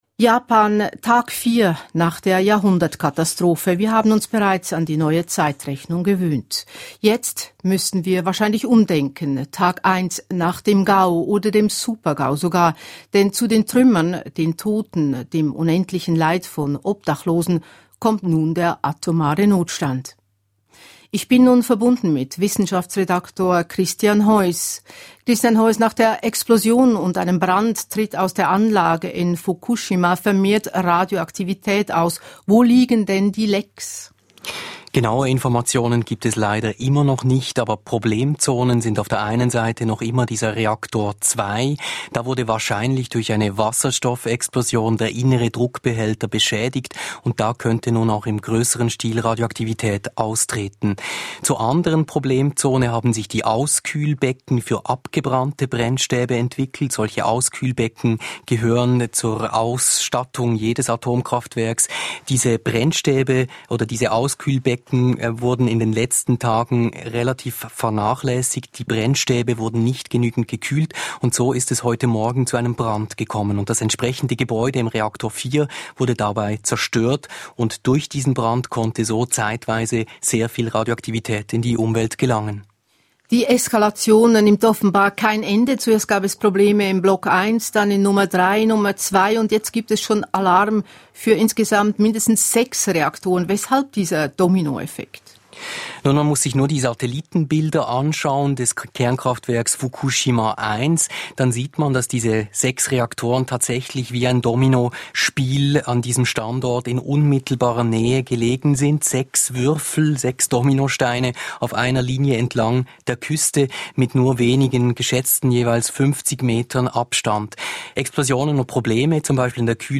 Auskünfte des Botschafters Urs Bucher über seine Arbeit während der aktuellen Krise.
Gespräch